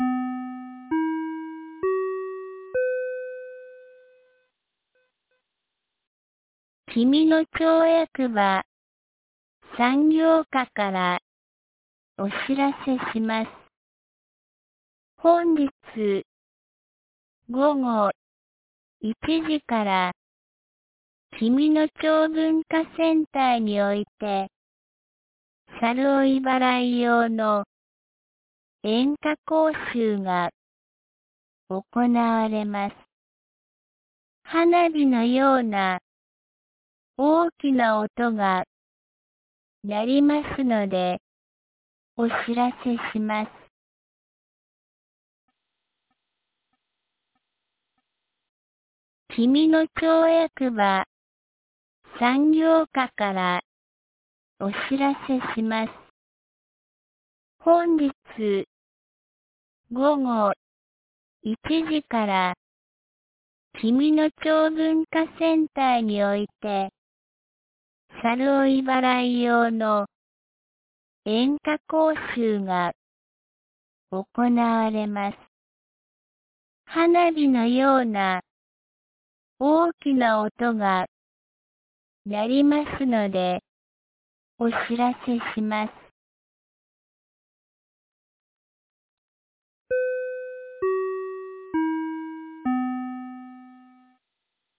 2025年10月23日 12時36分に、紀美野町より下神野地区へ放送がありました。